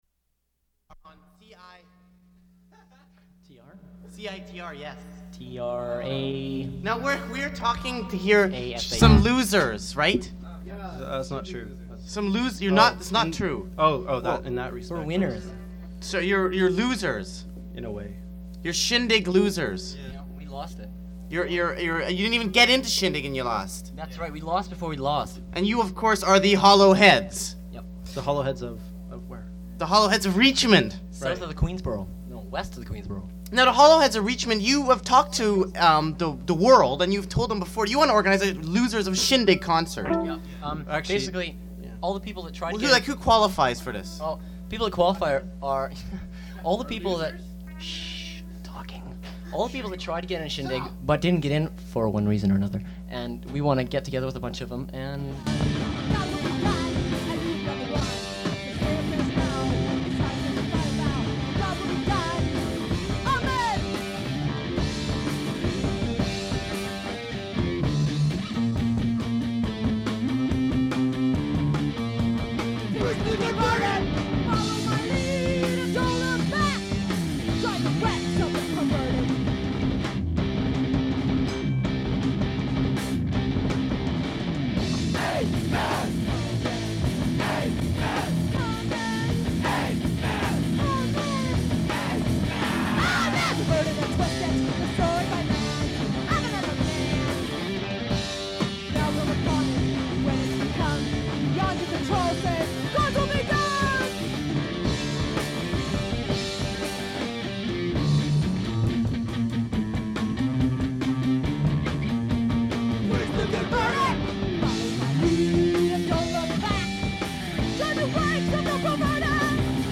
Recording of a live performance
musical group